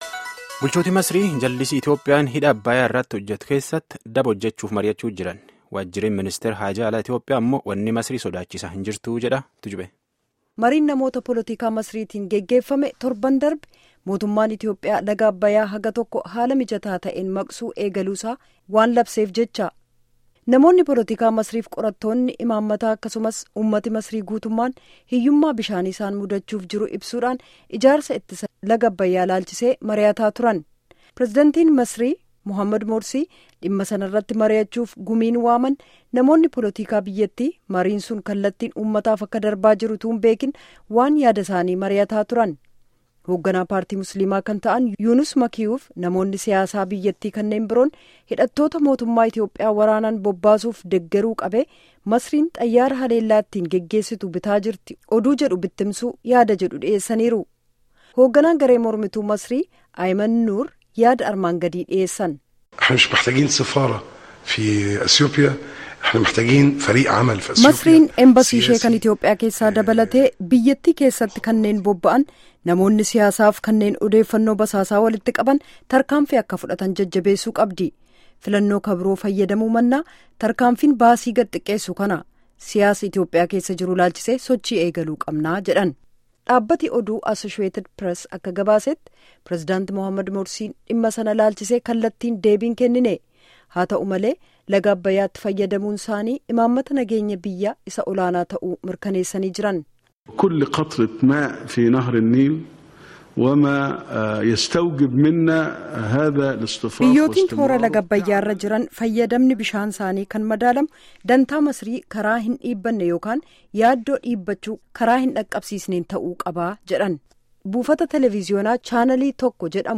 Gabaasaa Guutuu Armaa Gaditti Caqasaa